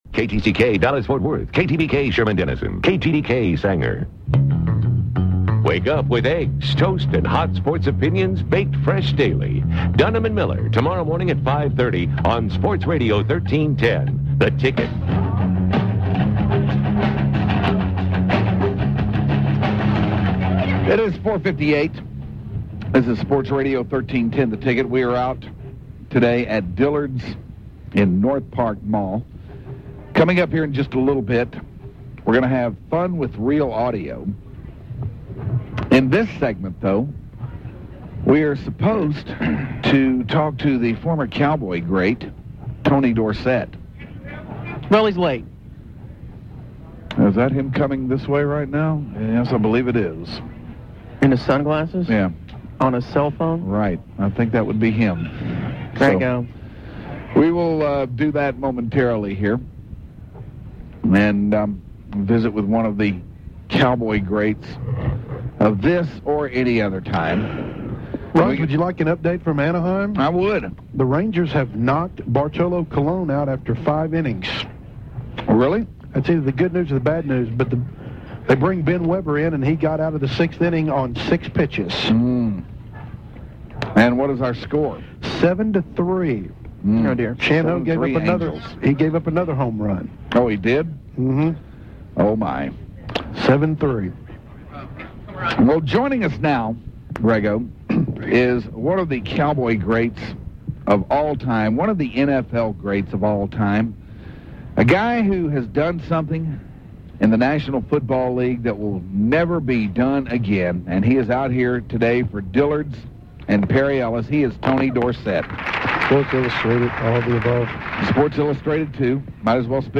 The Hardliners interview The Great Tony Dorsett